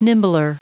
Prononciation du mot nimbler en anglais (fichier audio)
nimbler.wav